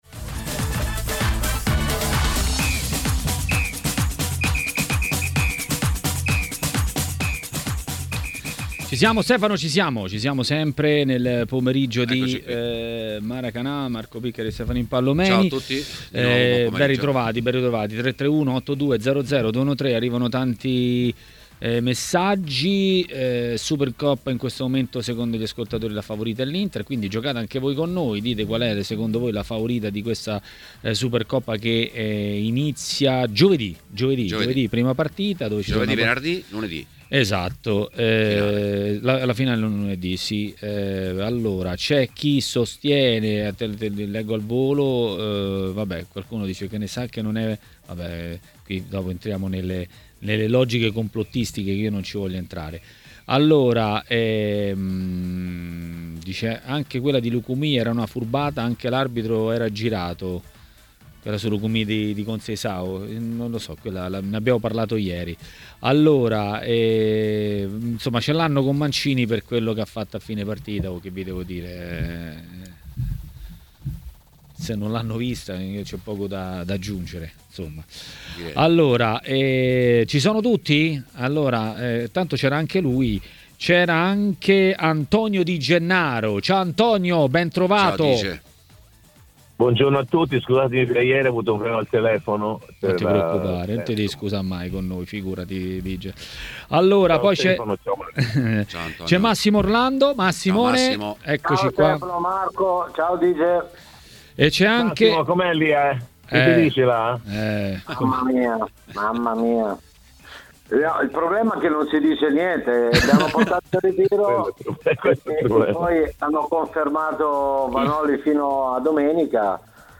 Massimo Orlando, ex calciatore, è intervenuto a Maracanà, nel pomeriggio di TMW Radio.